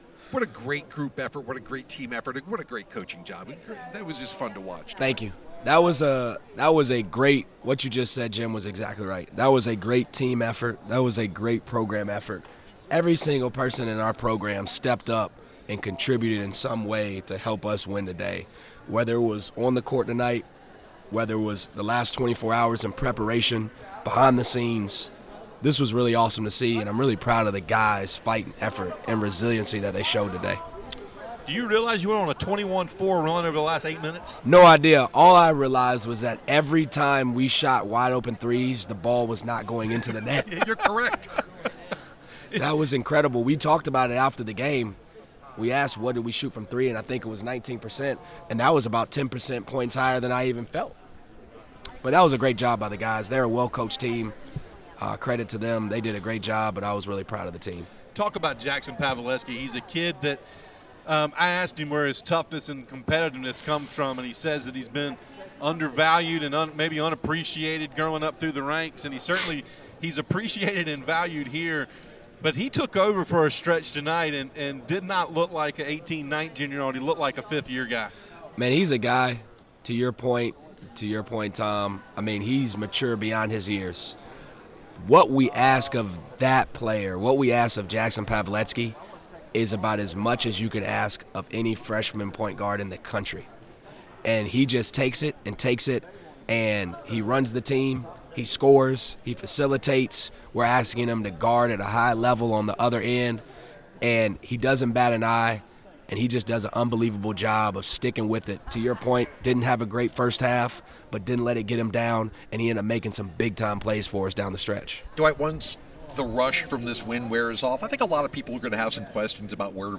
postgame interview
Postgame Audio